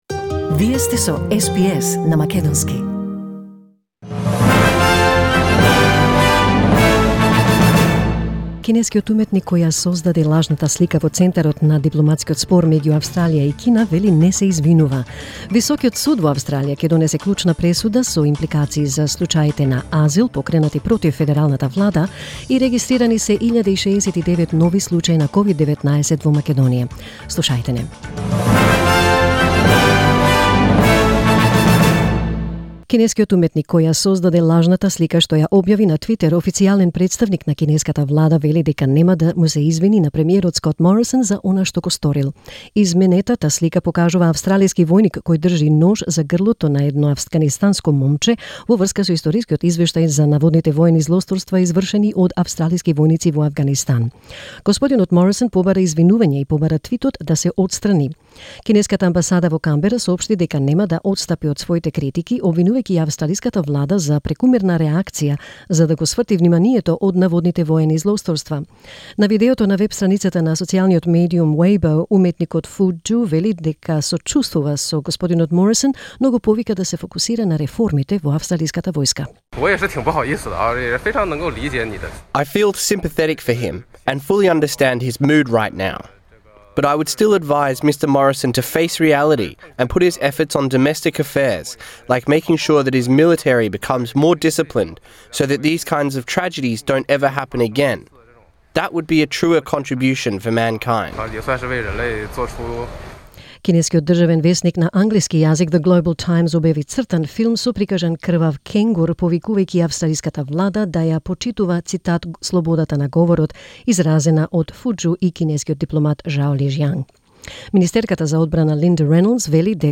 SBS News in Macedonian 2 December 2020